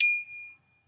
Bell Ding
bell clang ding metal metallic ping ring ting sound effect free sound royalty free Sound Effects